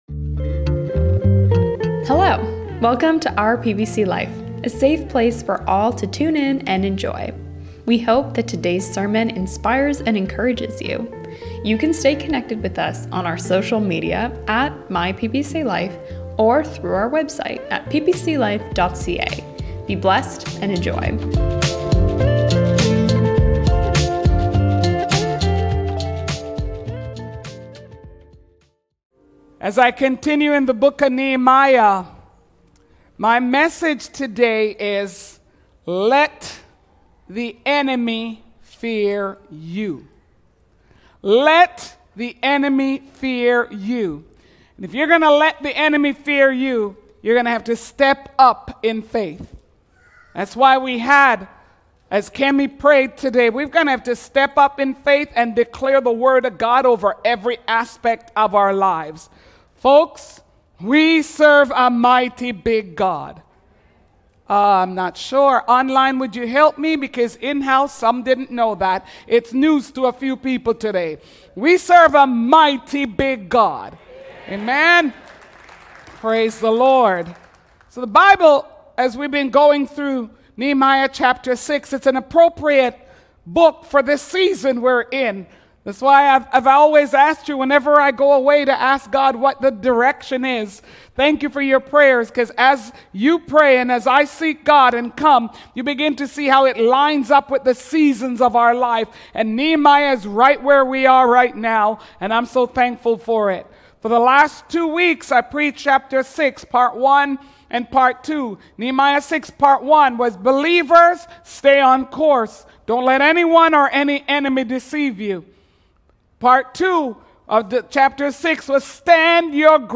In today's sermon